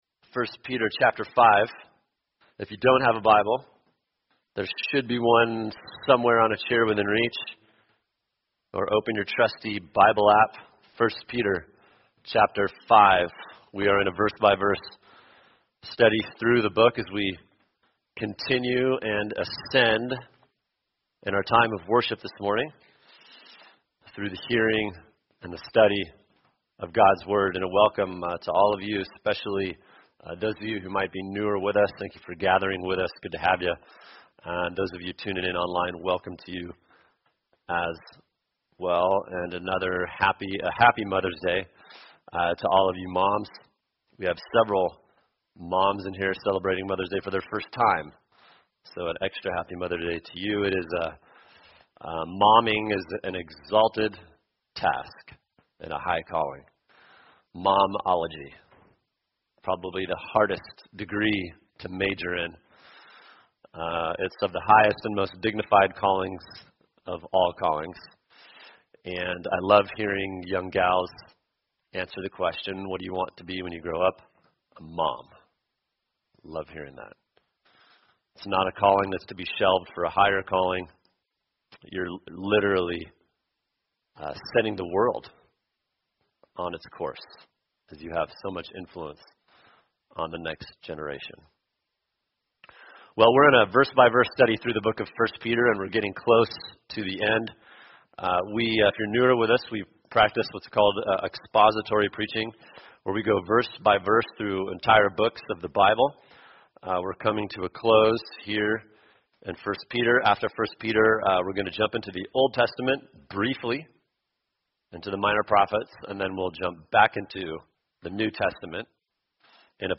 [sermon] 1 Peter 5:5 God’s Plan For Young Men | Cornerstone Church - Jackson Hole